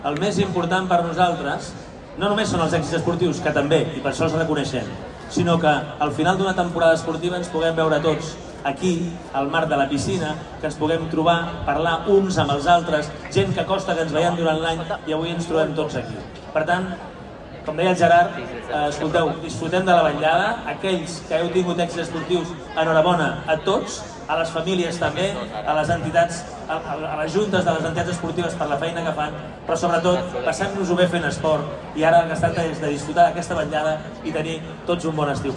Aquest vespre s’ha celebrat a la Piscina Municipal d’Estiu del CIES la gala de reconeixement als èxits esportius que organitza l’Ajuntament de Martorell.
Xavier Fonollosa, alcalde de Martorell